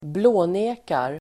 Ladda ner uttalet
blåneka verb (vardagligt), flatly deny [informal] Grammatikkommentar: A & Uttal: [²bl'å:ne:kar] Böjningar: blånekade, blånekat, blåneka, blå|nekar Definition: neka mot klara bevis (deny in the face of hard evidence)